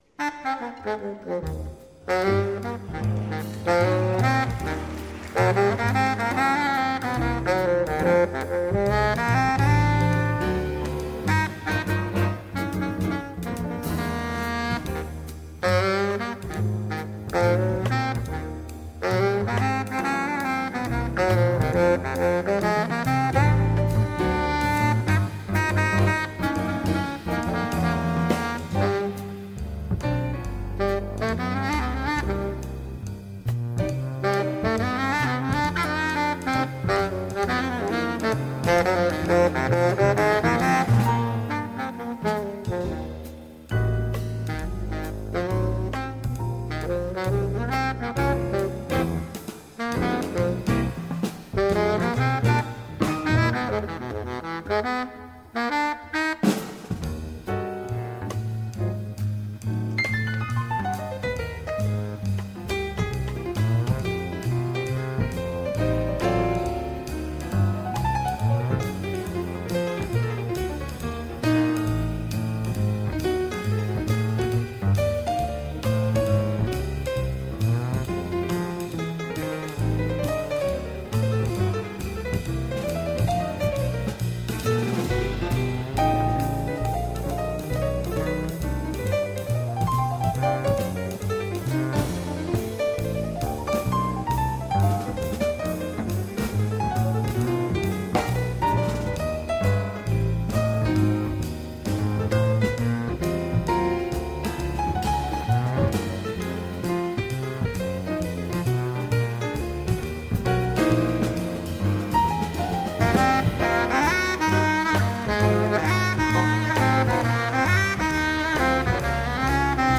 Icons of Jazz Series: The 50's.
live from Leipzig